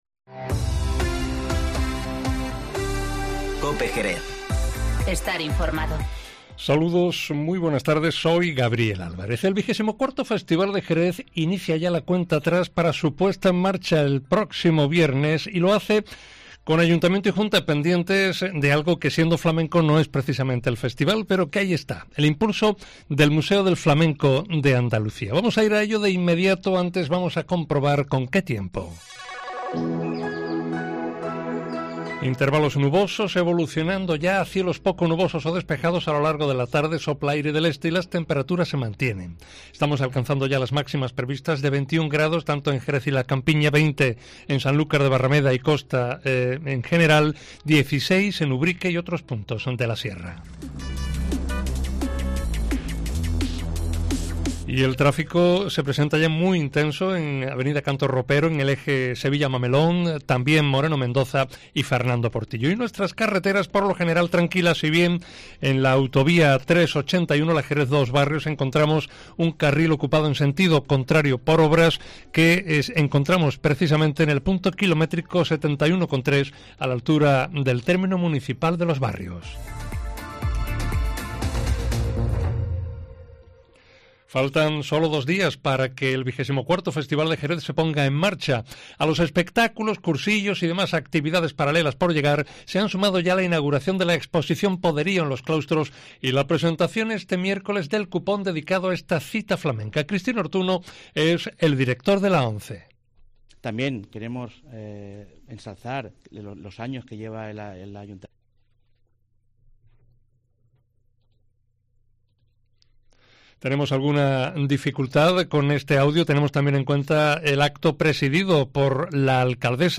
Informativo Mediodía COPE en Jerez 19-02-20